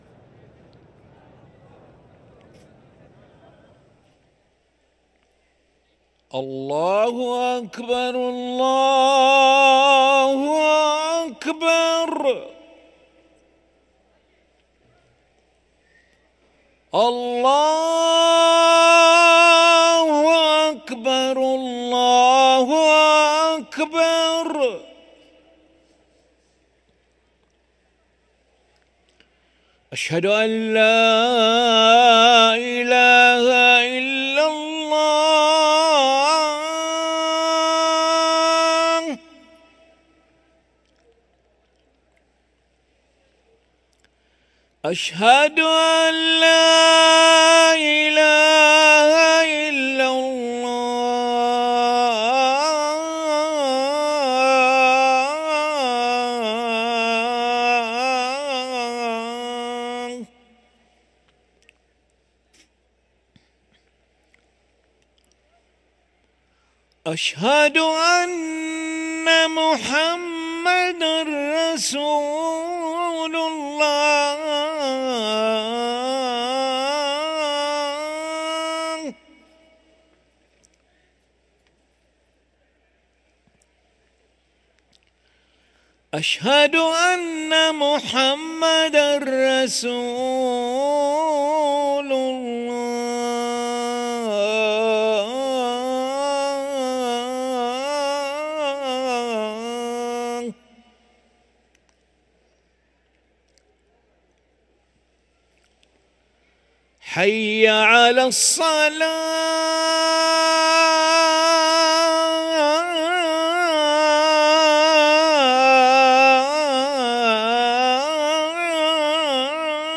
أذان العشاء للمؤذن علي ملا الخميس 22 صفر 1445هـ > ١٤٤٥ 🕋 > ركن الأذان 🕋 > المزيد - تلاوات الحرمين